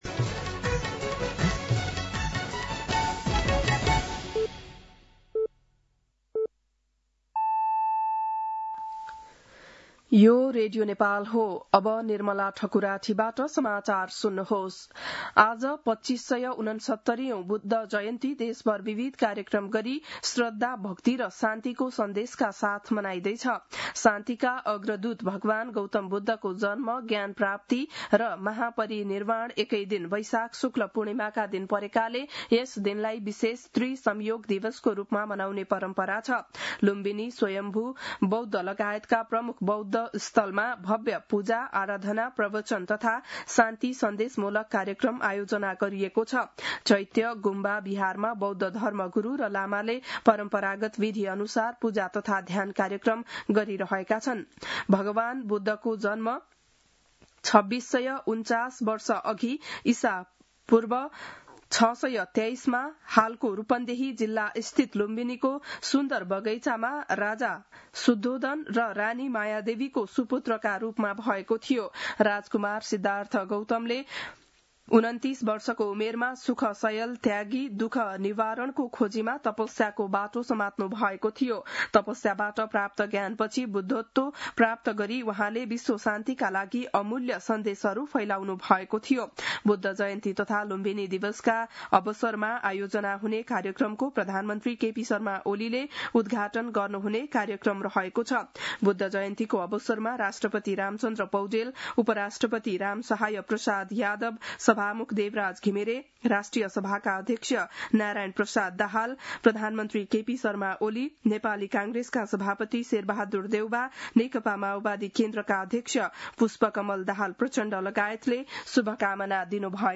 बिहान ११ बजेको नेपाली समाचार : २९ वैशाख , २०८२